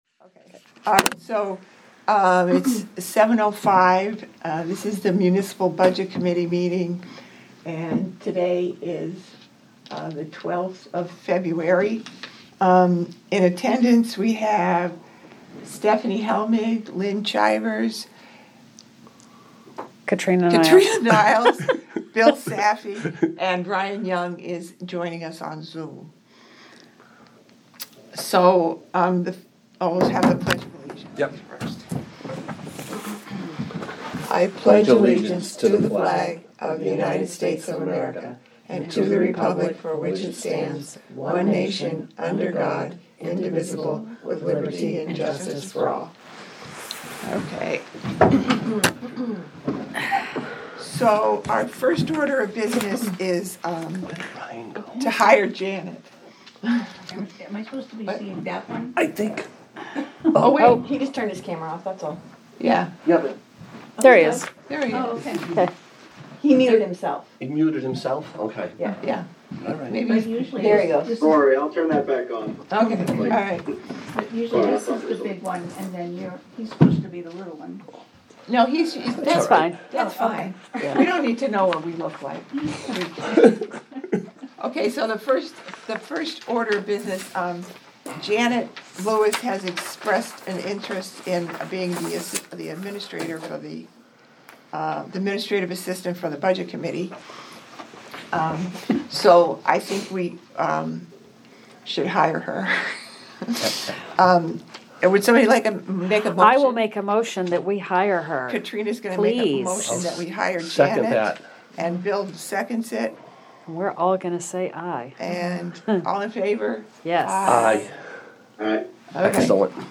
Audio recordings of committee and board meetings.
Budget Committee Meeting